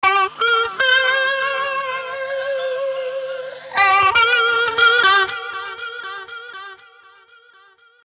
描述：推送字符串
Tag: 120 bpm Blues Loops Guitar Electric Loops 1.35 MB wav Key : Unknown